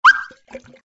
SA_watercooler_appear_only.ogg